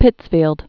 (pĭtsfēld)